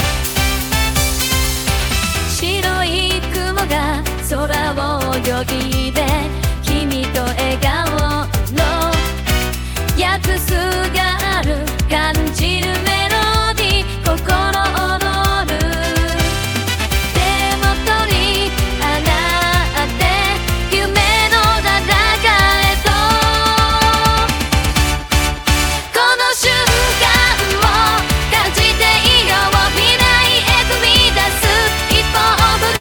できた楽曲がこちらです。